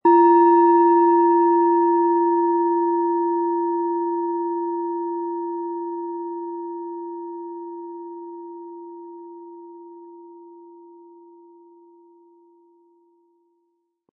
Planetenschale® Besänftigt sein & Befreit sein mit Chiron, Ø 10,2 cm, 100-180 Gramm inkl. Klöppel
Planetenton 1
Die Planetenklangschale Chiron ist handgefertigt aus Bronze.
Um den Originalton der Schale anzuhören, gehen Sie bitte zu unserer Klangaufnahme unter dem Produktbild.